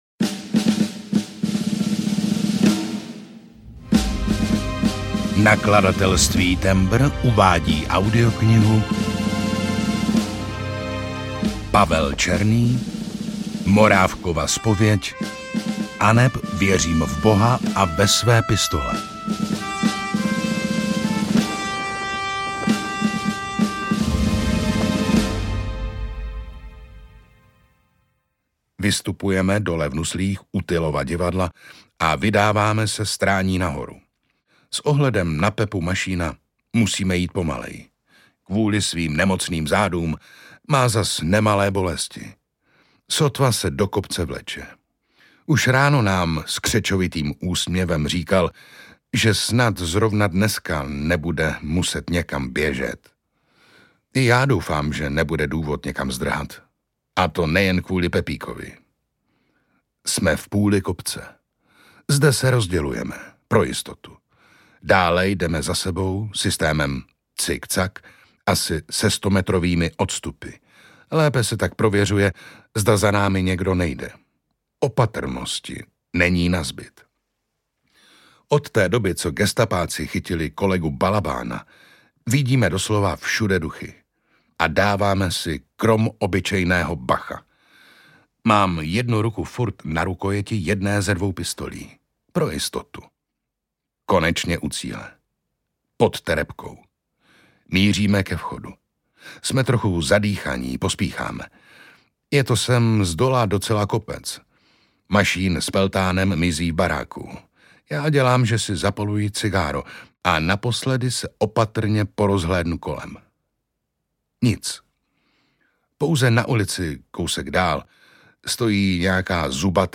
Ukázka z knihy
• InterpretOndřej Vetchý, Jiří Dvořák, Lukáš Hlavica